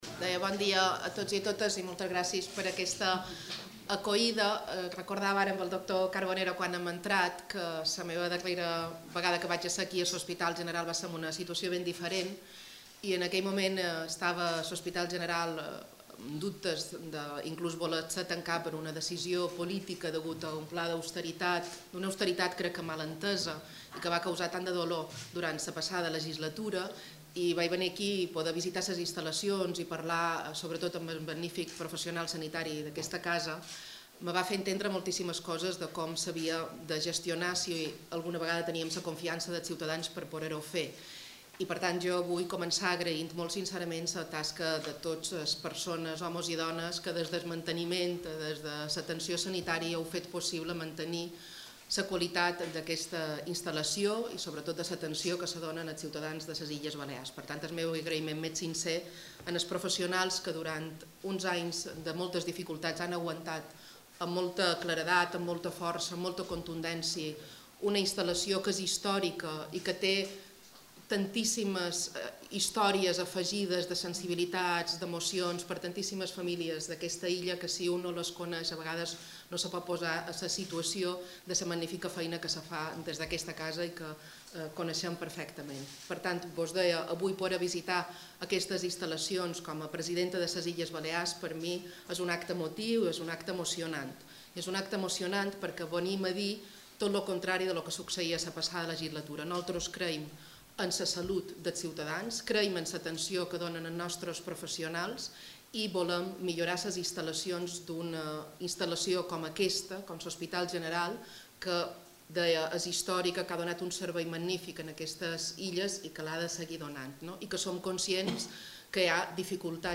Declaraciones-de-la-presidenta-Francina-Armengol-en-el-Hospital-General.mp3